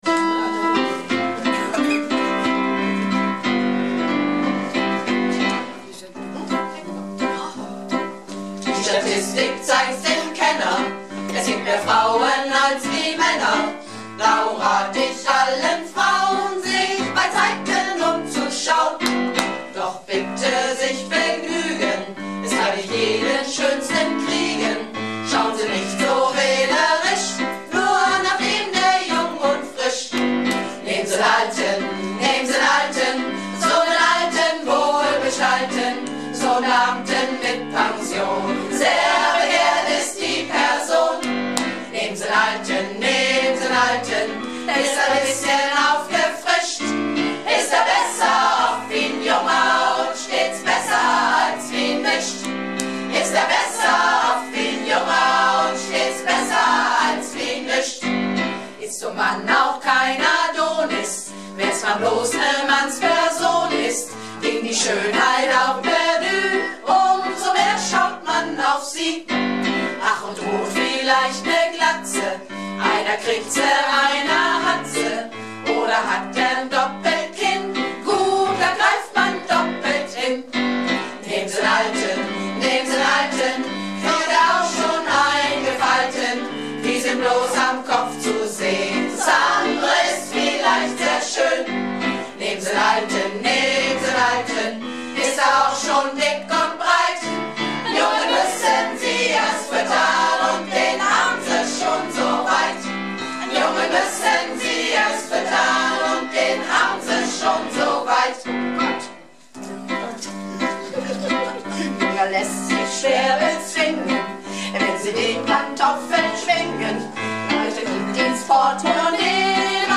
Malle Diven - Probe am 18.11.14